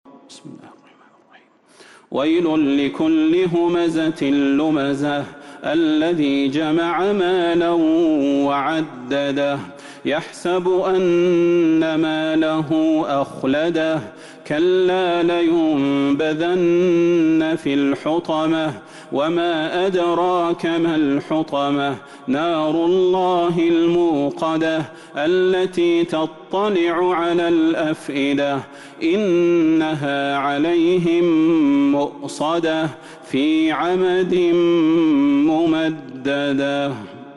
سورة الهمزة Surat Al-Humazah من تراويح المسجد النبوي 1442هـ > مصحف تراويح الحرم النبوي عام ١٤٤٢ > المصحف - تلاوات الحرمين